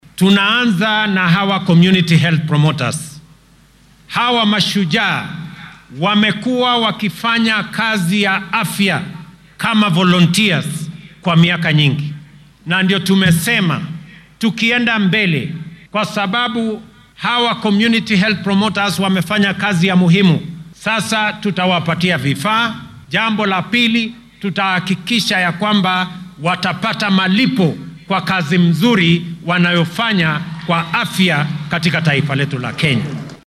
Madaxweynaha dalka William Ruto oo maanta khudbad ka jeedinayay munaasabadda xuska maalinta halyeeyada wadanka ee Mashujaa Day ayaa sheegay in dhammaan kenyaanka ay heli doonaan adeegyo caafimaad oo tayo leh, ay awoodaan sidoo kalena aan lagu kala takoorin.